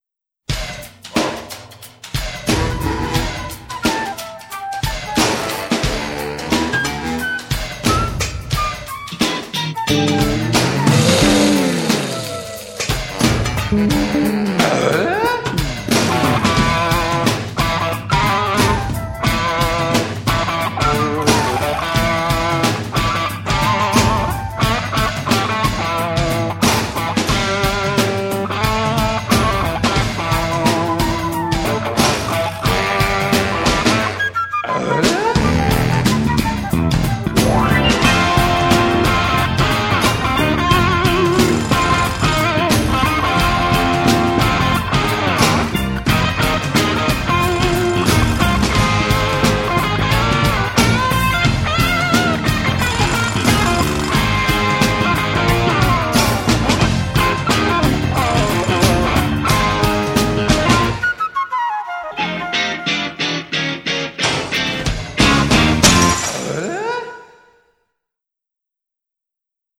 Theme Song (WAV 12.4 MB)